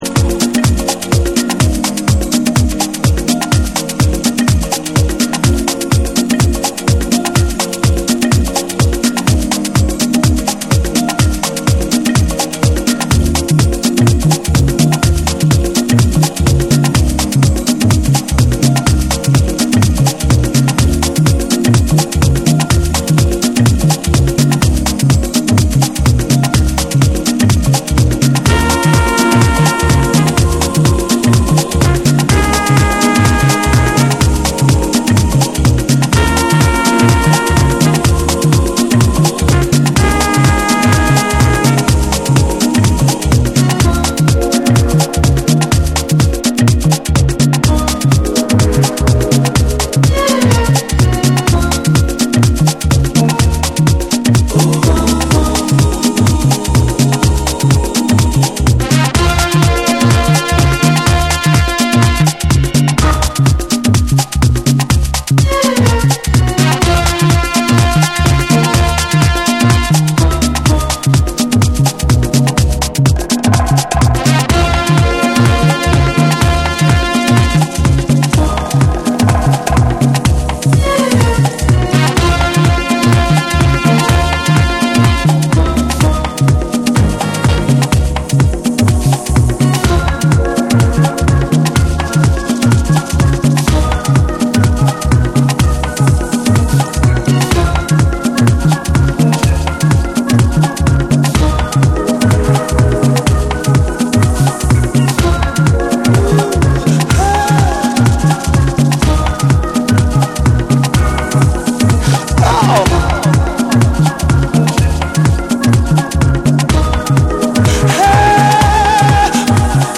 ジャジーなサックスやフルートをフィーチャしたハウス・ナンバー
TECHNO & HOUSE